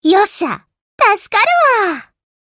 She speaks in a Kansai dialect.